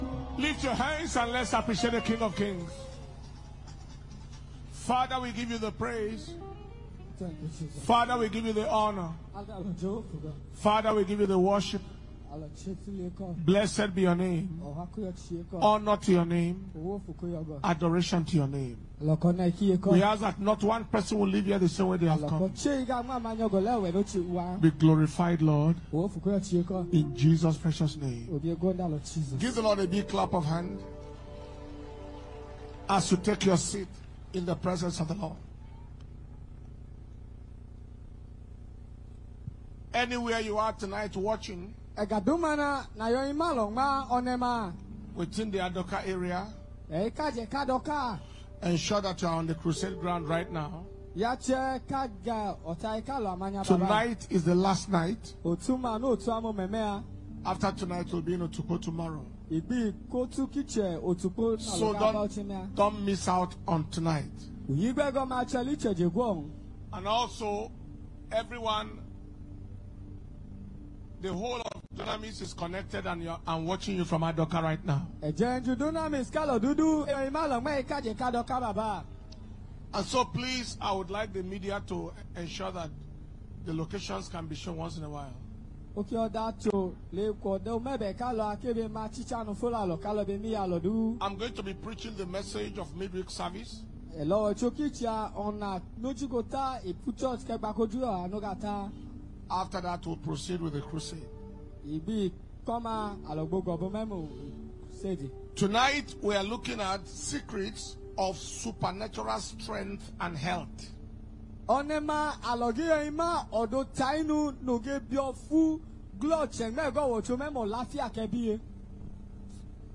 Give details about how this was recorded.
Secrets of Supernatural Strength And Health - The Life of Moses mp3 - Power Communion Service